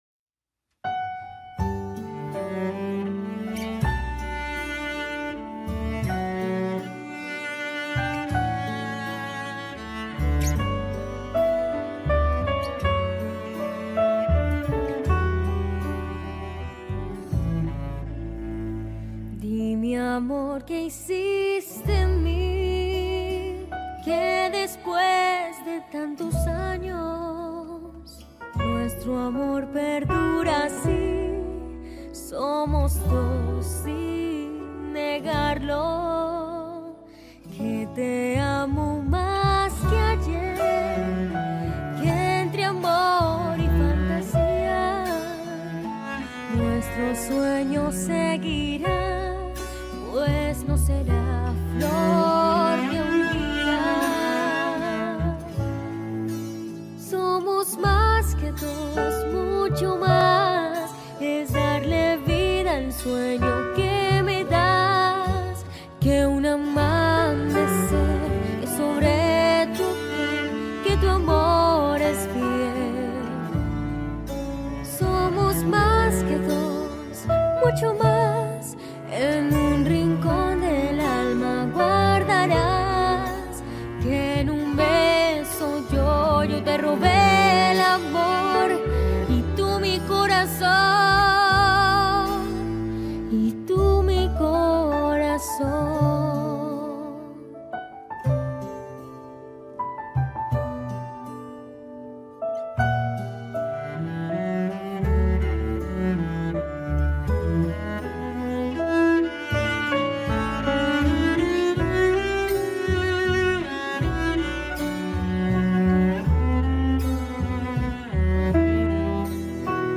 Pasillo